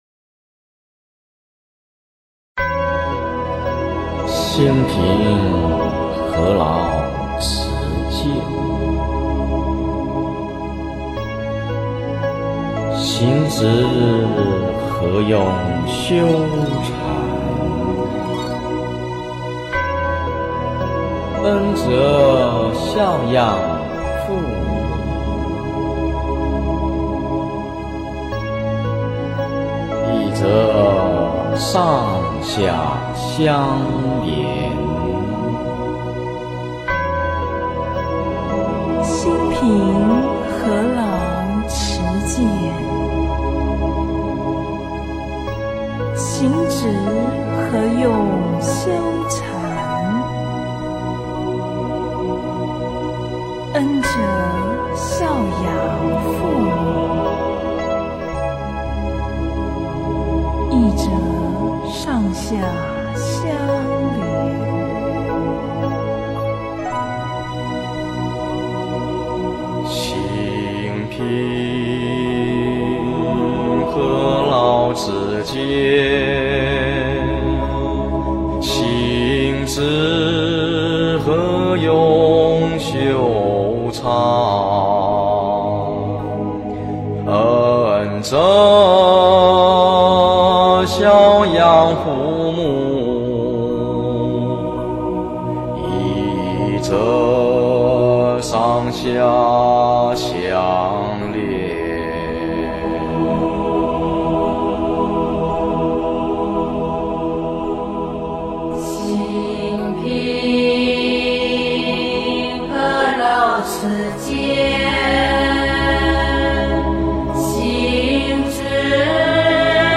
無相頌2 - 诵经 - 云佛论坛